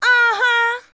AHA  2.wav